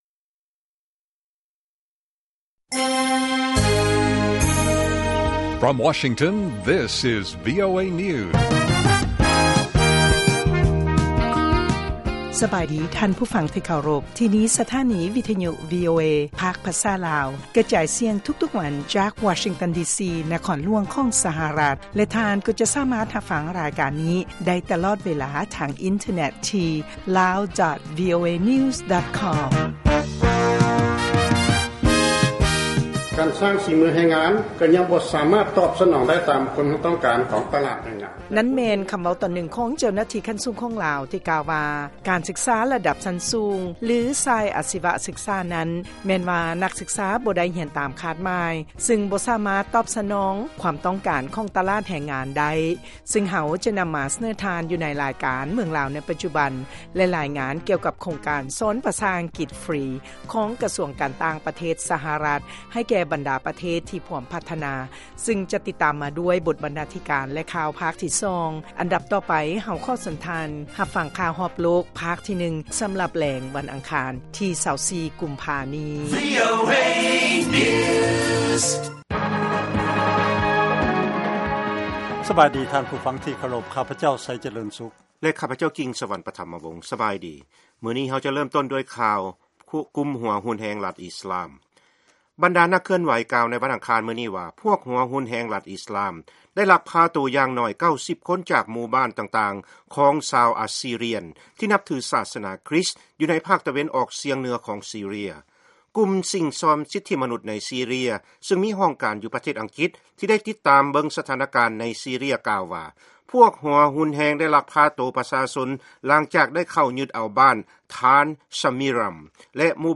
ວີໂອເອພາກພາສາລາວ ກະຈາຍສຽງທຸກໆວັນ ເປັນເວລາ 30 ນາທີ.